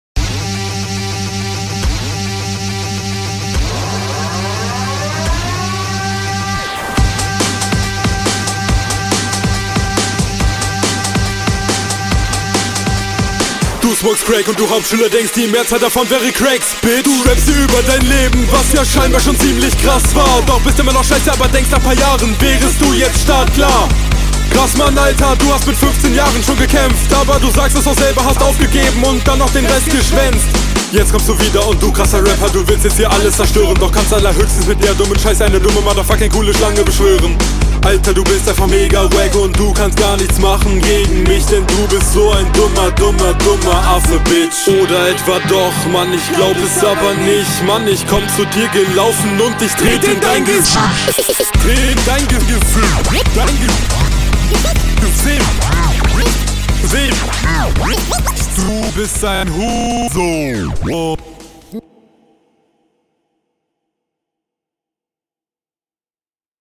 Flow: Der Flow ist sehr cool!
Flow: Super Flow und kommt richtig gut auf dem Beat.
Echt starke schneller Flow Text: Schöne starke Lines mit Gegnerbezug.